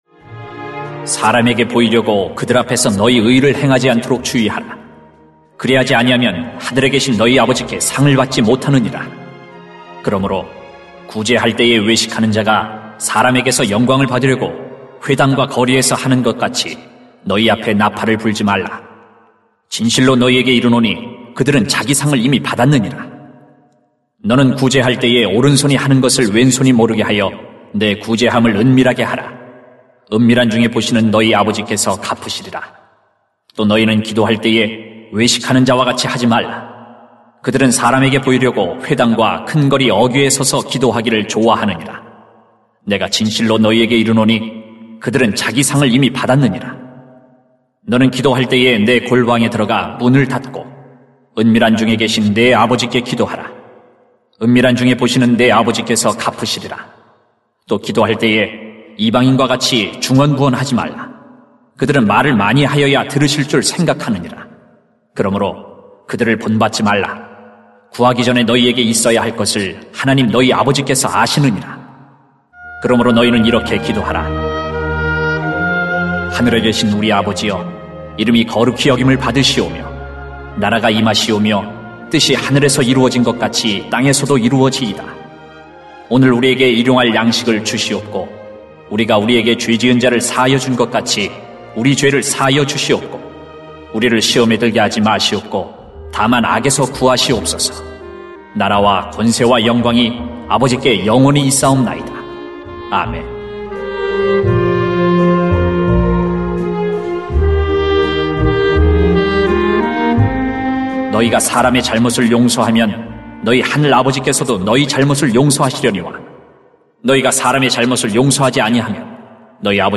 [마 6:1-18] 경건한 습관을 기릅시다 > 새벽기도회 | 전주제자교회